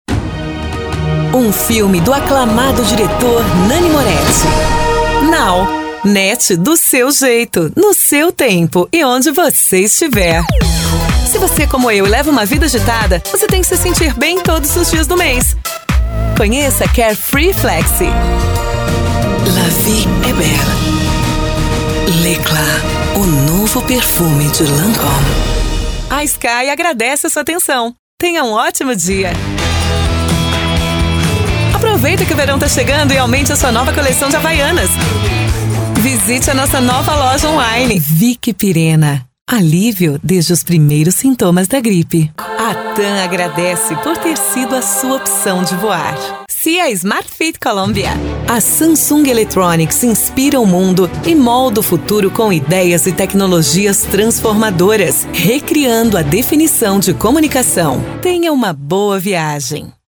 企业宣传